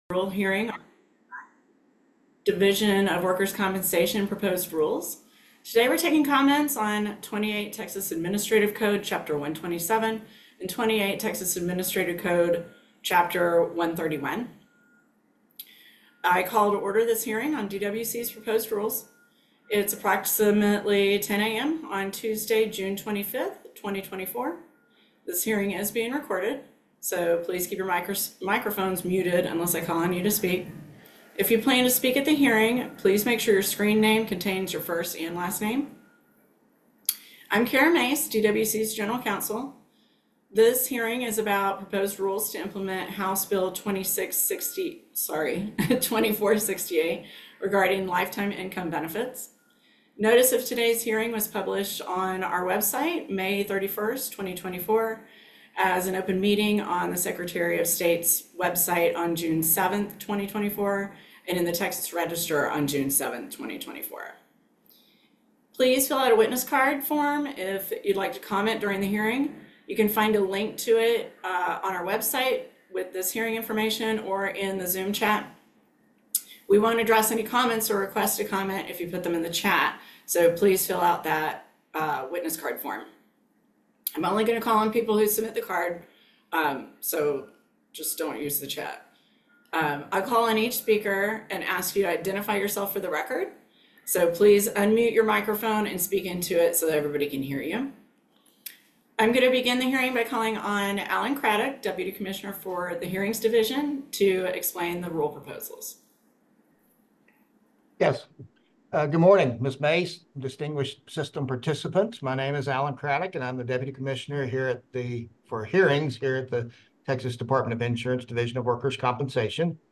January 23, 2023 Public Hearing - Designated Doctor Billing and Reimbursement Rules